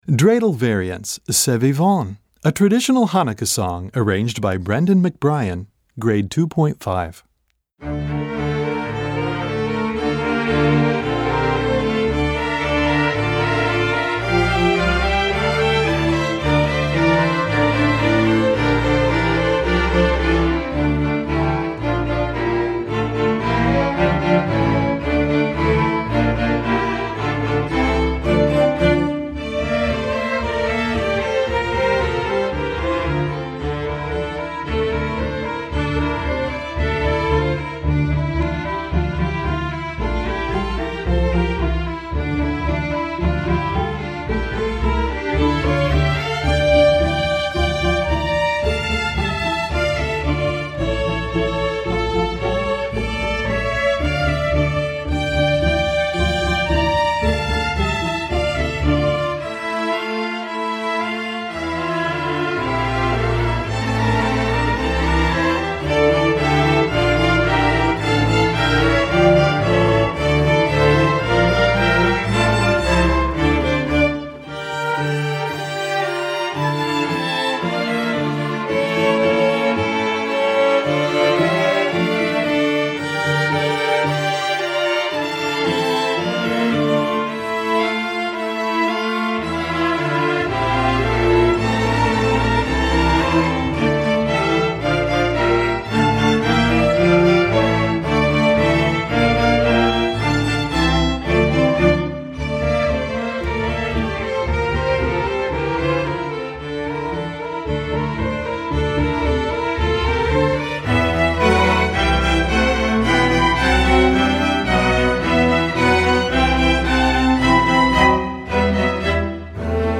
Komponist: Traditional Hanukkah
Gattung: Streichorchester
Besetzung: Streichorchester